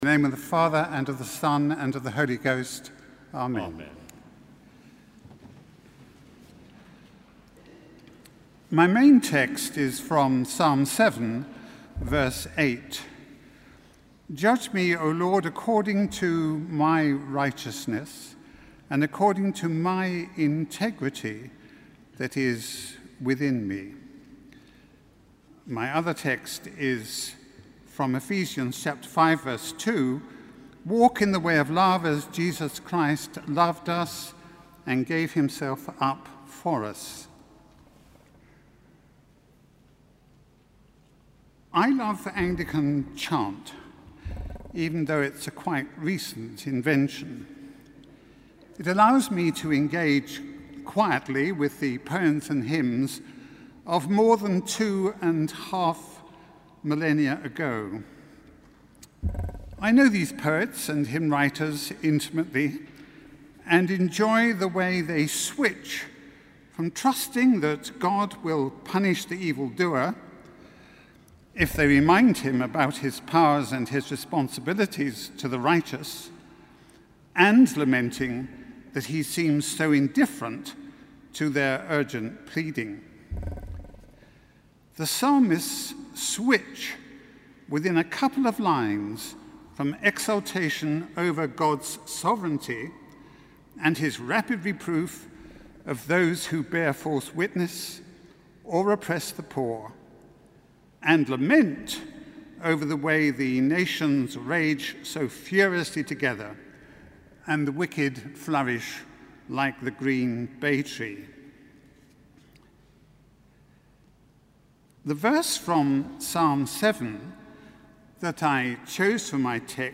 Sermon: Evensong 16 Feb 2014